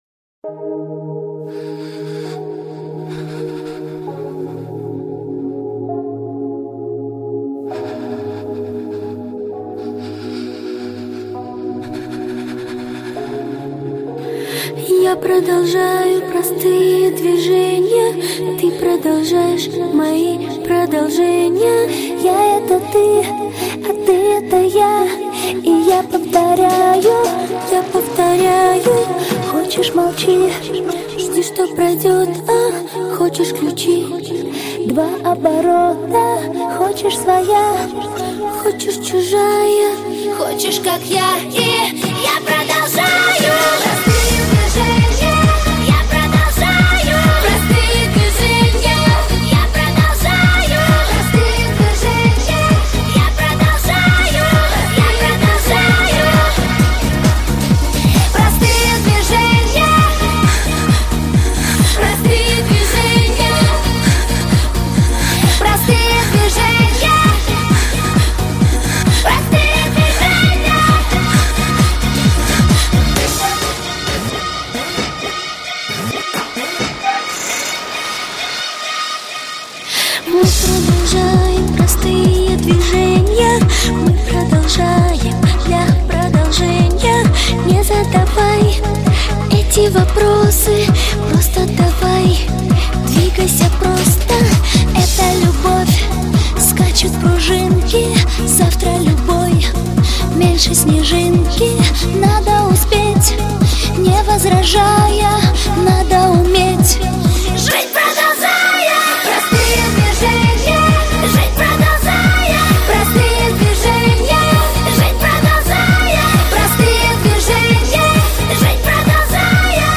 Трек размещён в разделе Поп / Казахская музыка.